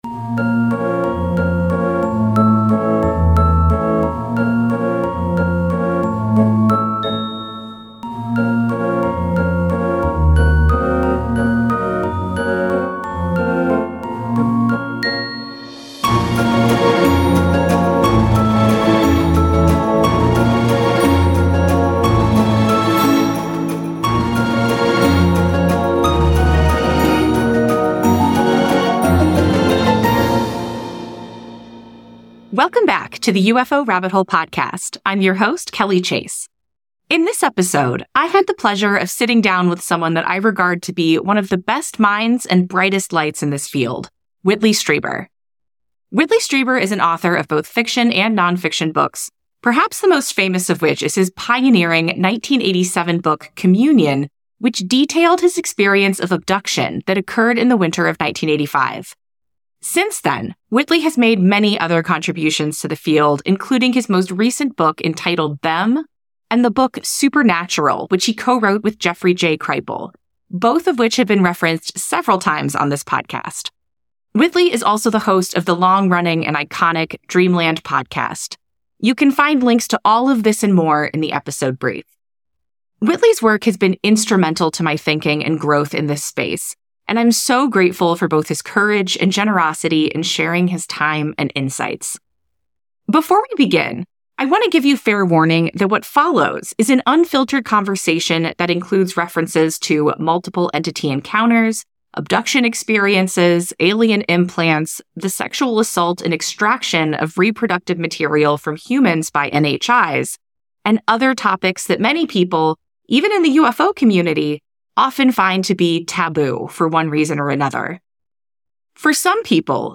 [The UFO Rabbit Hole] Ep 33: An Interview with Whitley Strieber: On Communion with Non-Human Intelligences